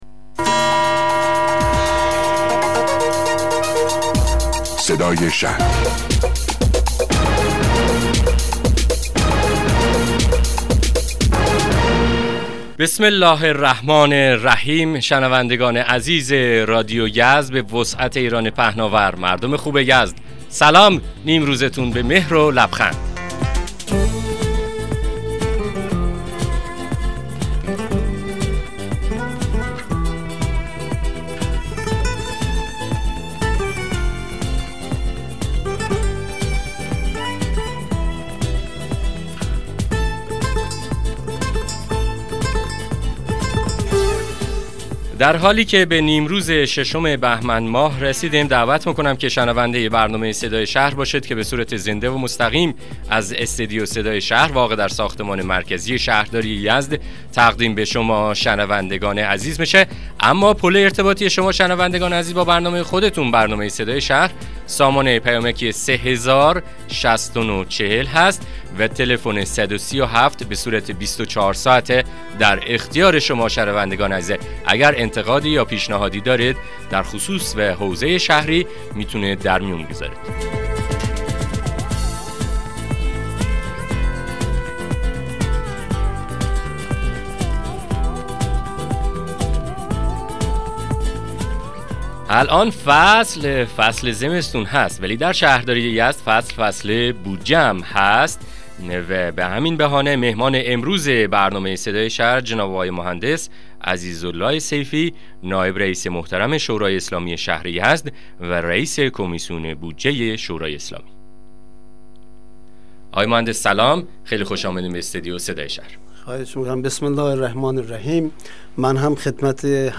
مصاحبه رادیویی برنامه صدای شهر با حضور عزیزاله سیفی رییس کمیسیون بودجه شورای اسلامی شهر یزد